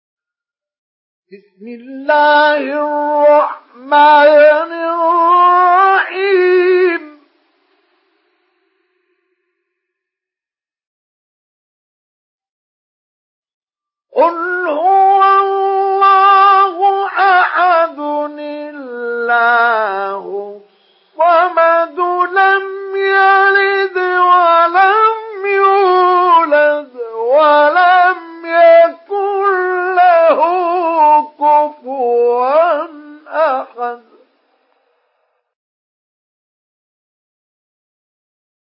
سورة الإخلاص MP3 بصوت مصطفى إسماعيل مجود برواية حفص